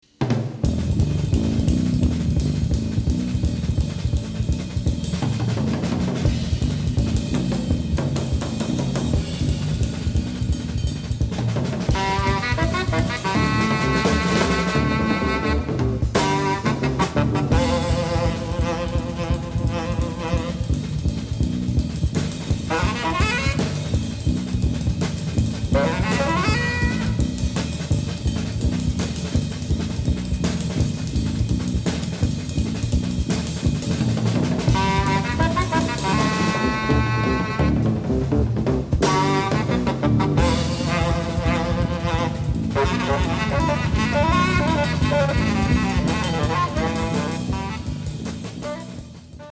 Tenor and Soprano Saxophone
Electric Bass
Drums and Congas